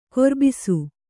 ♪ korbisu